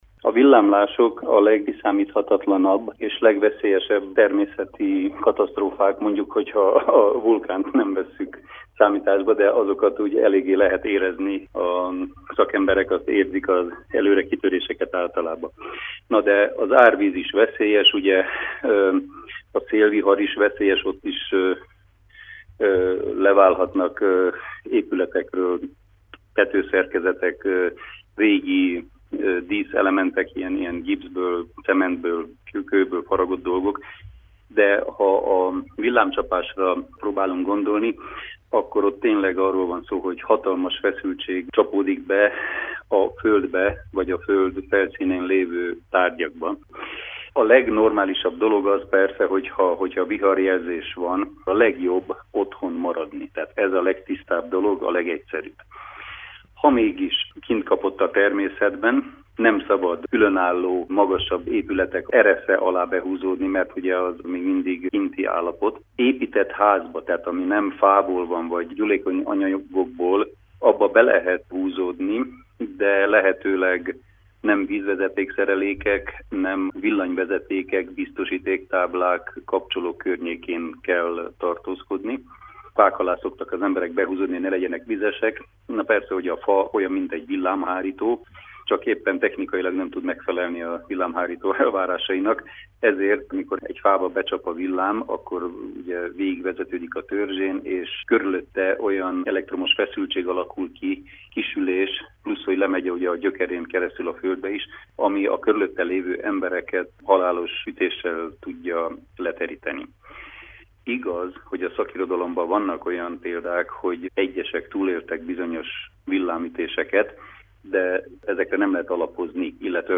Riporter: